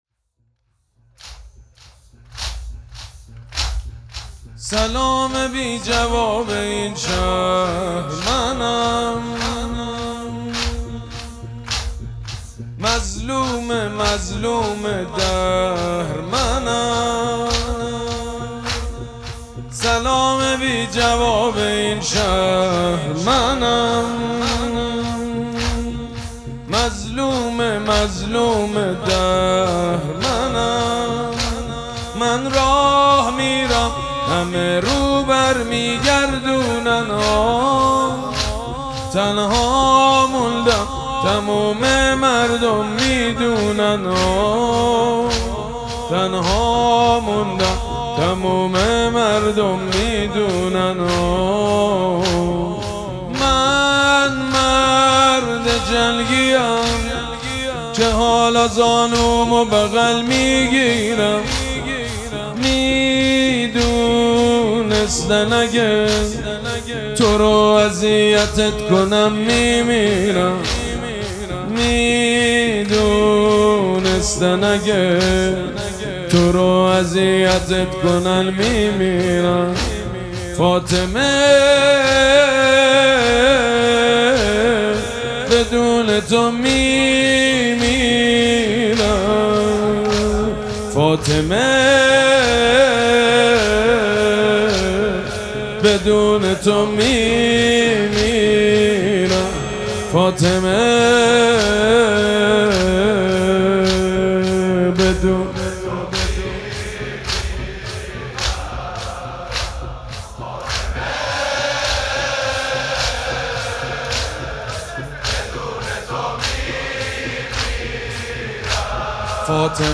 مراسم عزاداری شب شهادت حضرت زهرا سلام‌الله‌علیها
حاج سید مجید بنی فاطمه